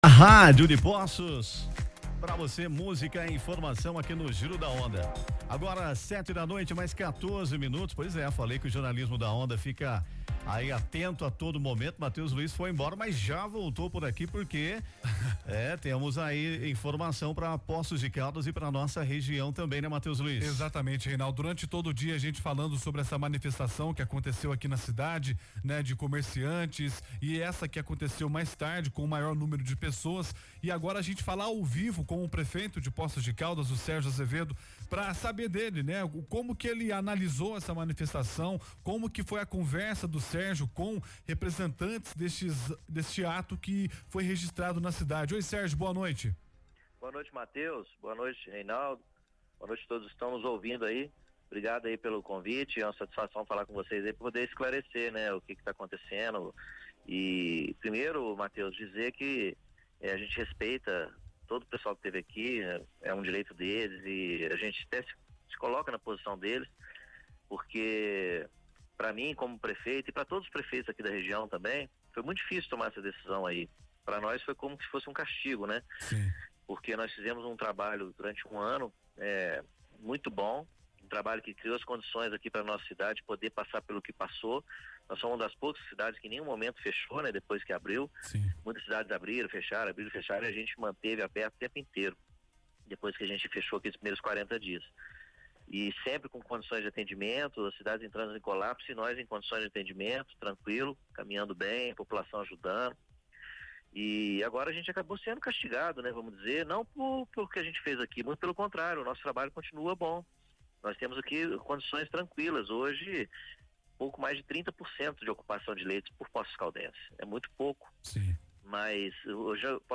Durante entrevista ao vivo por telefone ao programa Giro da Onda, o prefeito de Poços de Calas, Sérgio Azevedo, falou sobre o protesto realizado nesta quarta-feira, 17, contra a adesão da cidade na Onda Roxa do Governo do Estado. Sérgio falou também que solicitou a suspensão da cobrança da Zona Azul, reivindicação em pauta no ato de hoje e disse ainda que irá encaminhar ao Governo de Minas um pedido de reavaliação das medidas restritivas.